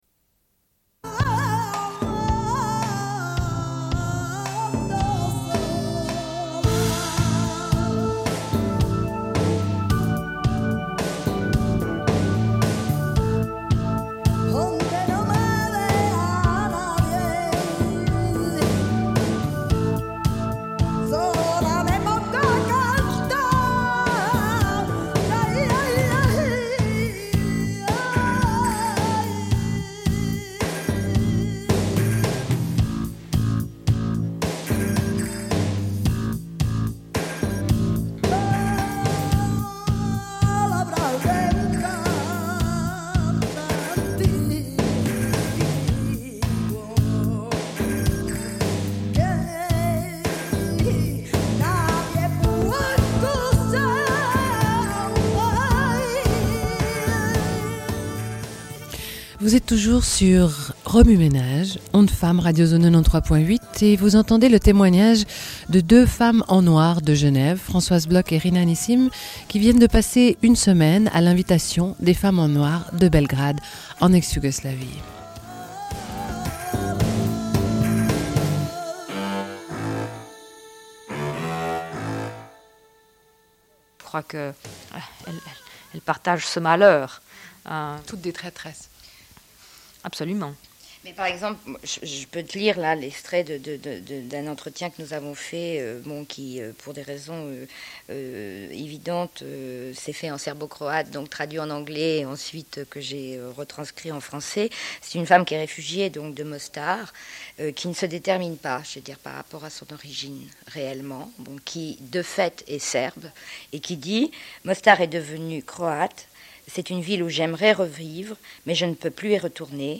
Une cassette audio, face B28:44